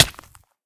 hit1.ogg